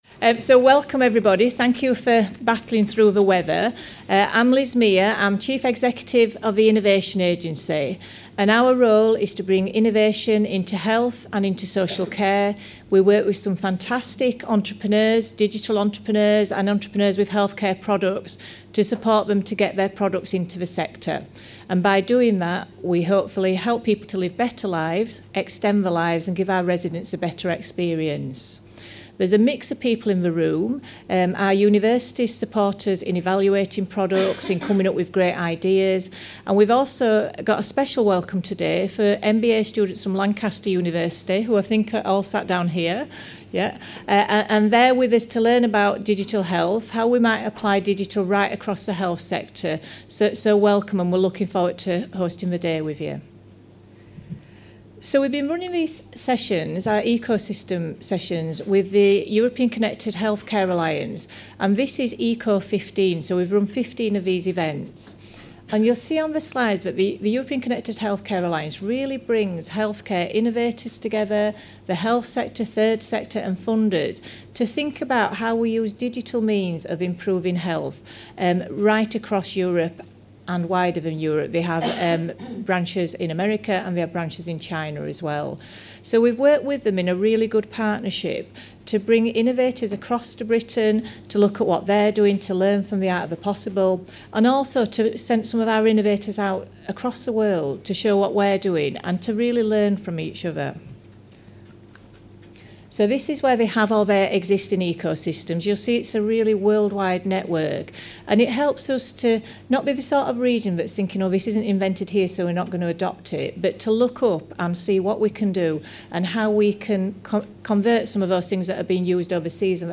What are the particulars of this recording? Introduces the Innovation Agency Eco 15 event